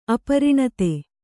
♪ apariṇate